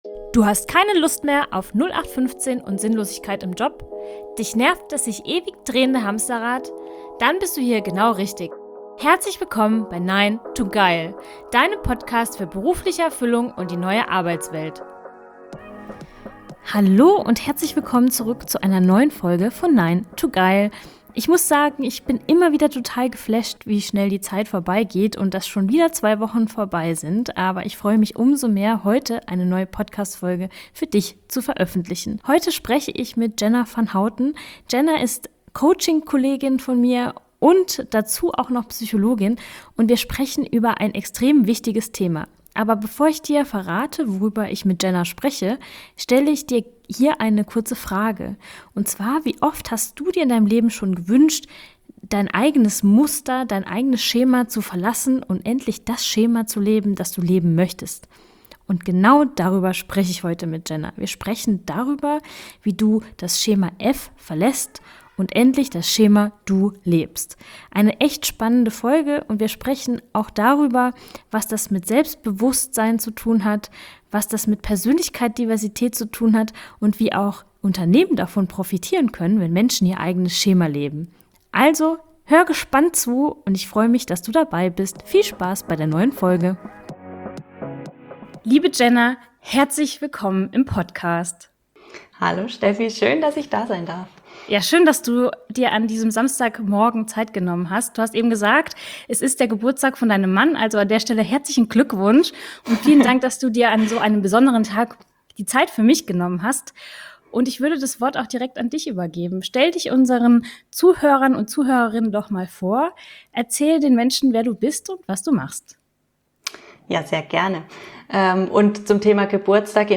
#019 - Schema DU statt Schema F! - Interview